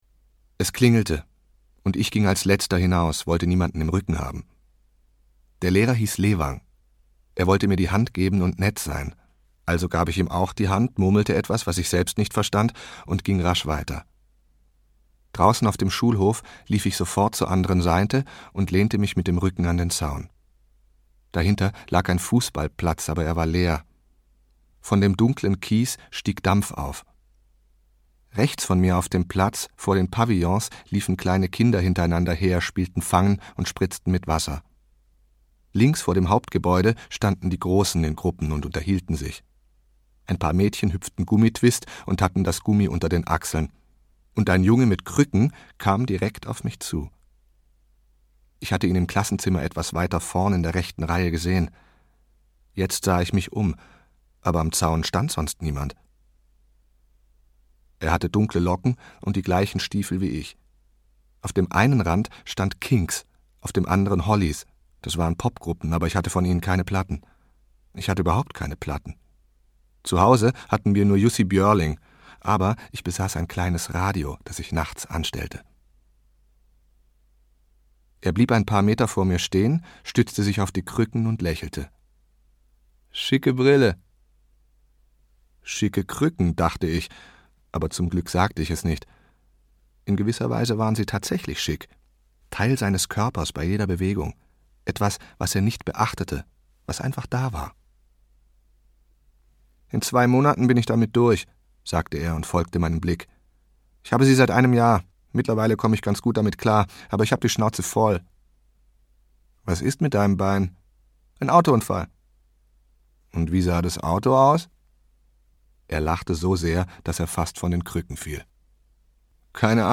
Ist schon in Ordnung - Per Petterson - Hörbuch